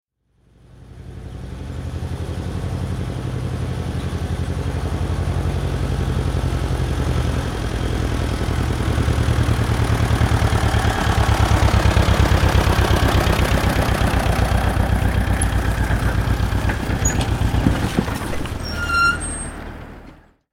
دانلود صدای تراکتور 4 از ساعد نیوز با لینک مستقیم و کیفیت بالا
جلوه های صوتی